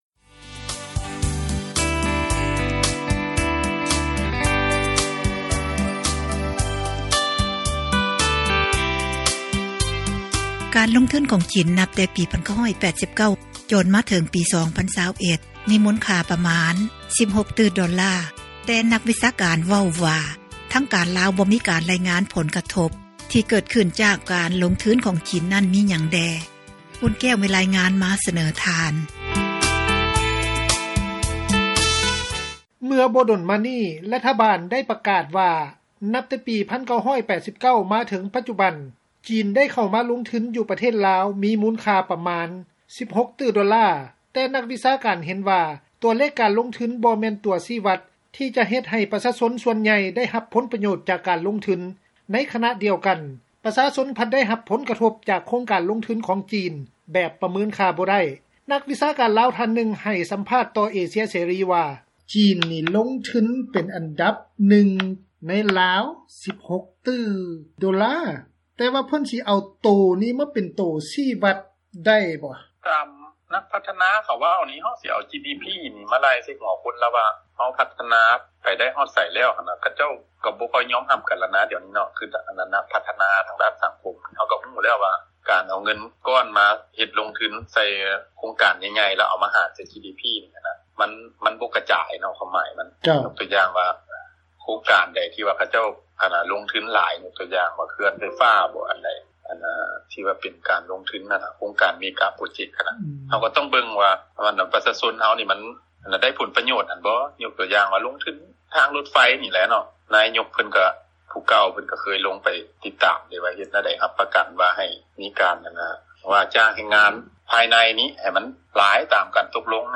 ນັກວິຊາການ ລາວ ທ່ານນຶ່ງ ໄດ້ໃຫ້ສັມພາດ ຕໍ່ວິທຍຸເອເຊັຽເສຣີ ວ່າ: